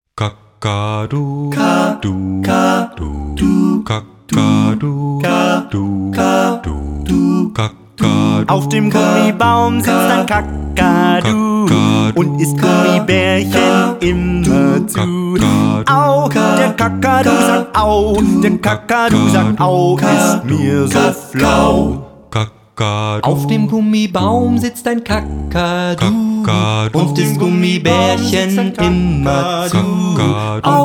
frisch und charmant